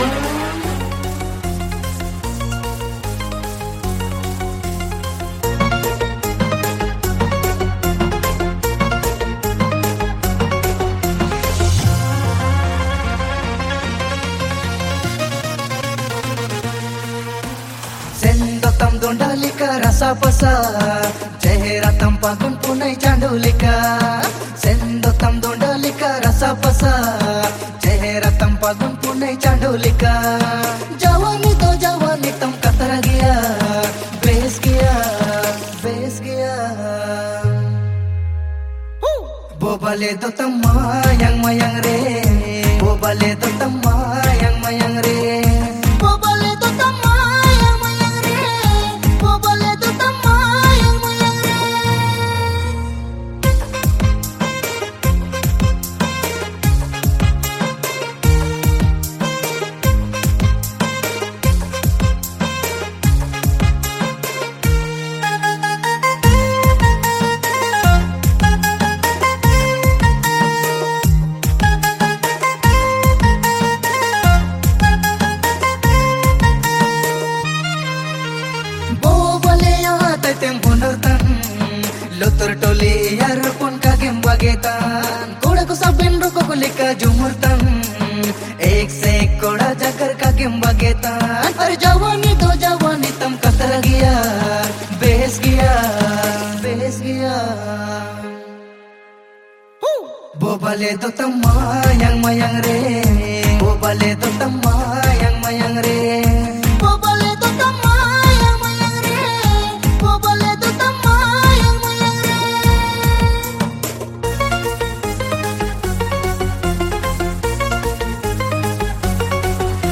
• Male Artist